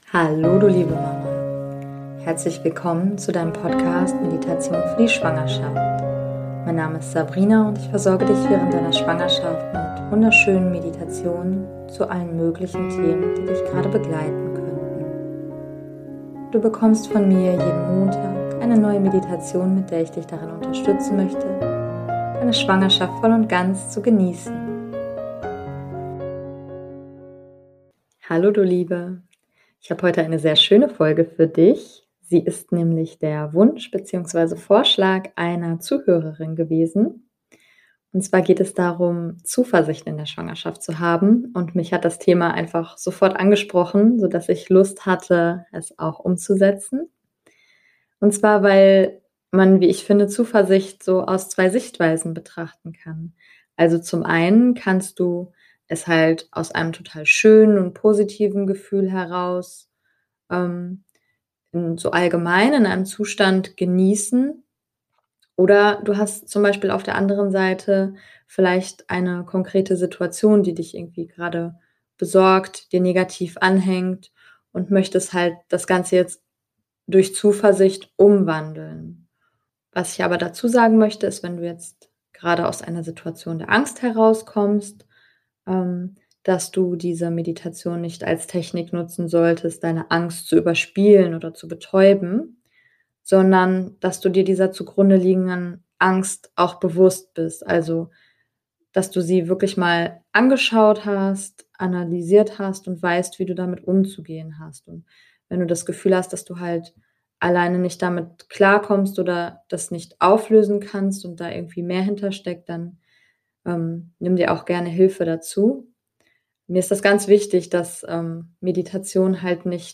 Du bist schwanger und möchtest einigen Themen gerne mit mehr Zuversicht und Optimismus entgegenschauen? Dann genieße diese Meditation und komme in die Entspannung.